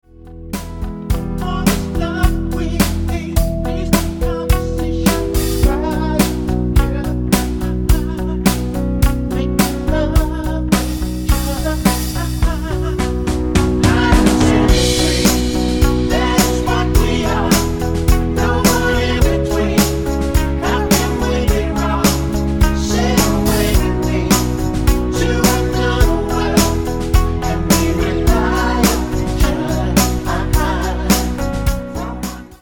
Tonart:B mit Chor